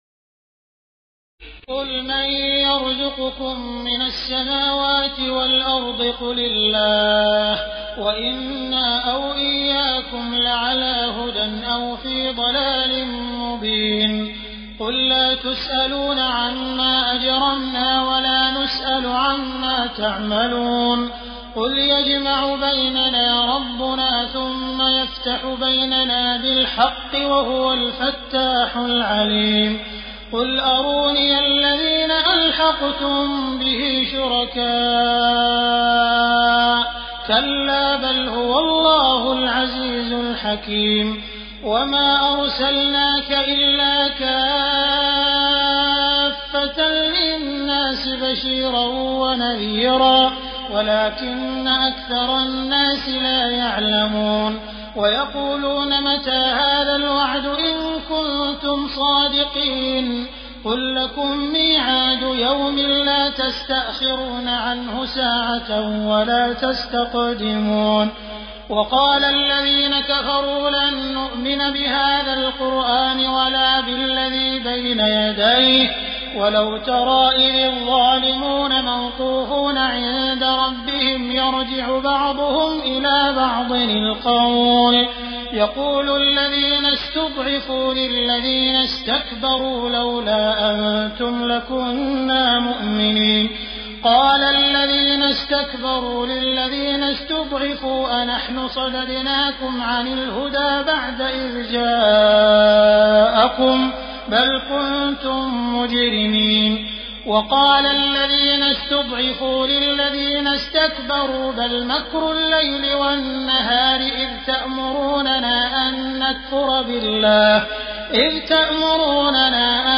تراويح ليلة 21 رمضان 1418هـ من سور سبأ (24-54) و فاطر و يس (1-32) Taraweeh 21 st night Ramadan 1418H from Surah Saba and Faatir and Yaseen > تراويح الحرم المكي عام 1418 🕋 > التراويح - تلاوات الحرمين